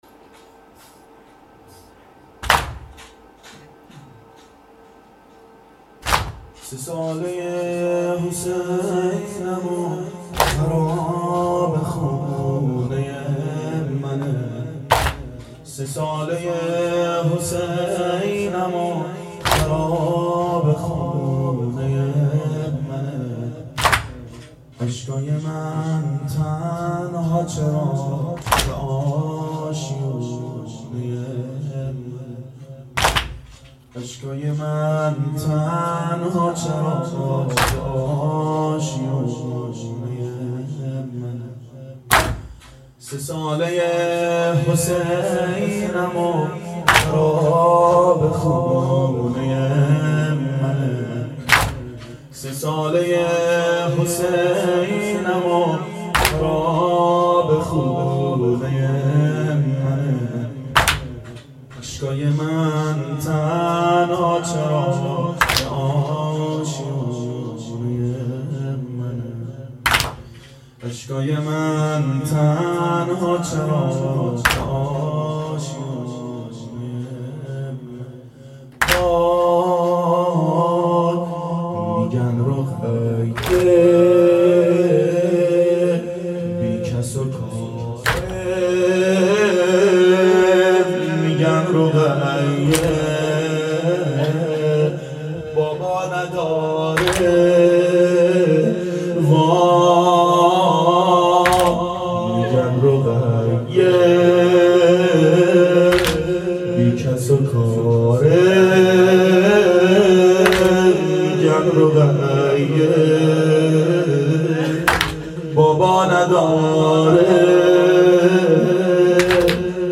شب اربعین 92 هیأت عاشقان اباالفضل علیه السلام منارجنبان